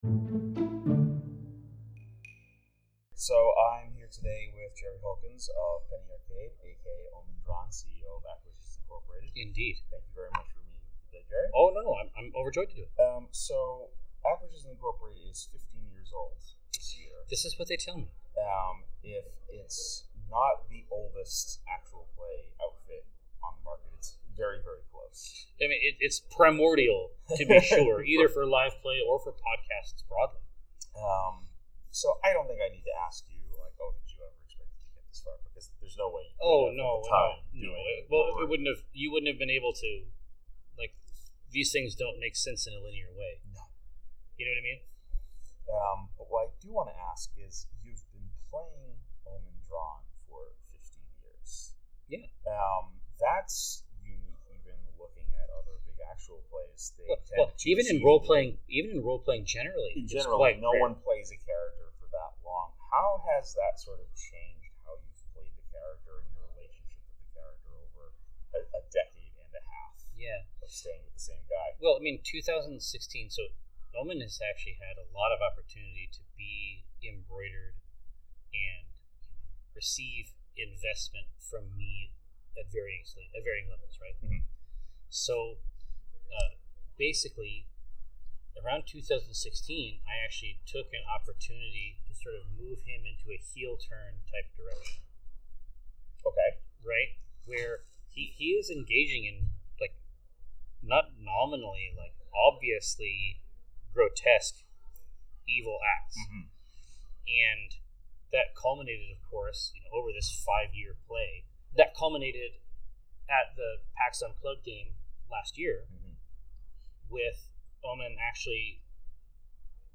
Interview – Cannibal Halfling Gaming
jerry-holkins-at-pax-east-23.mp3